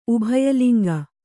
♪ ubhaya liŋga